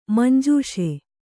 ♪ manjūṣe